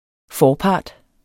Udtale [ ˈfɒː- ]